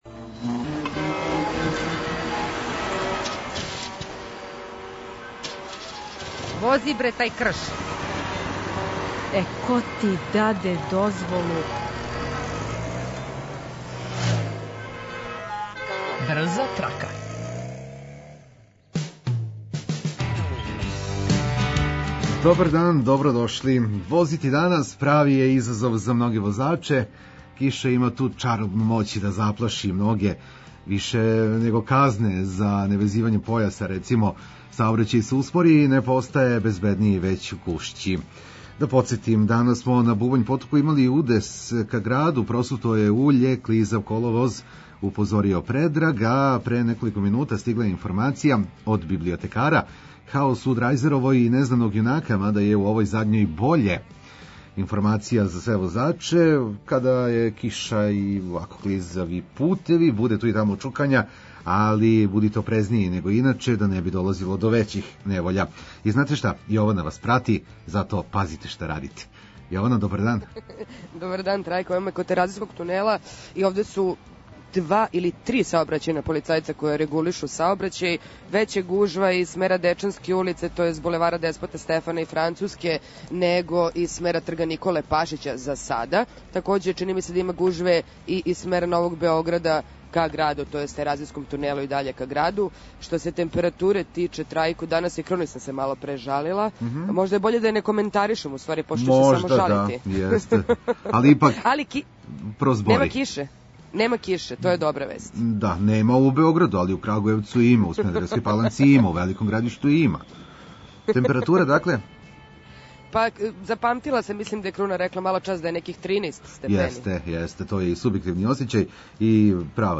преузми : 33.12 MB Брза трака Autor: Београд 202 У време највећих гужви, пређите у Двестадвојкину брзу траку.